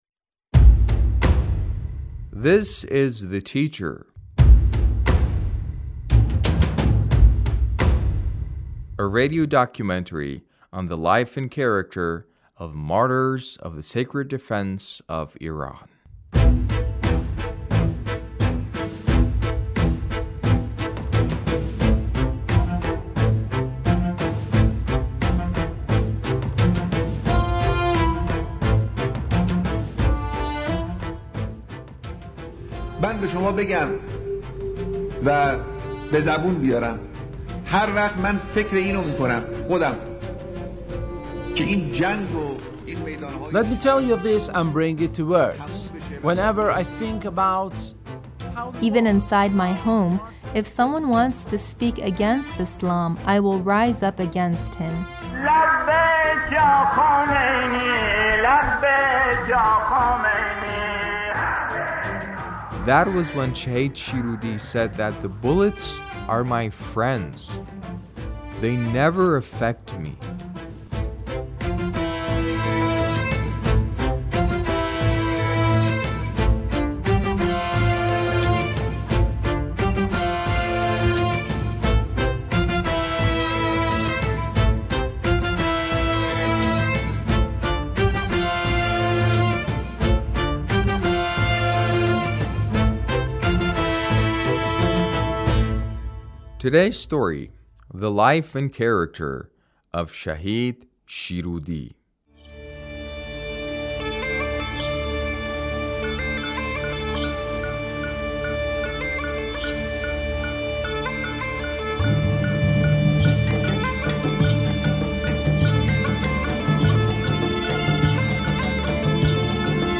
A radio documentary on the life of Shahid Ali Akbar Shiroudi- Part 2